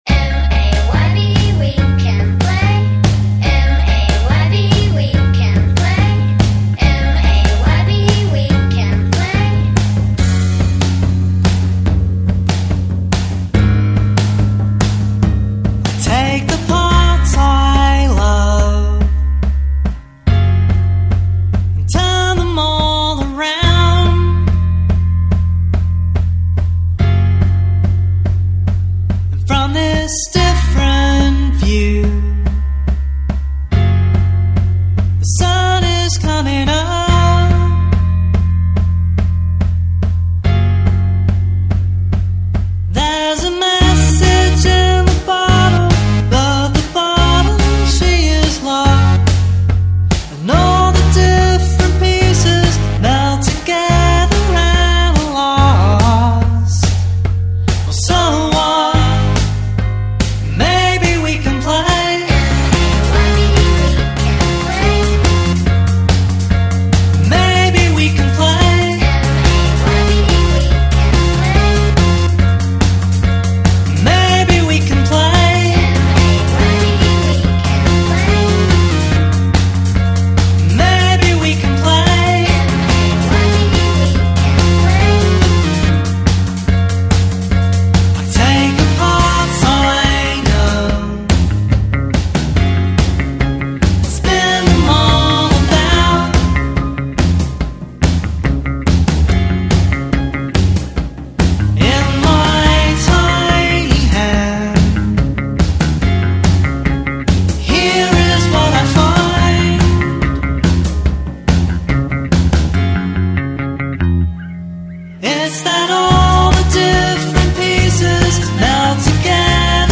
rock group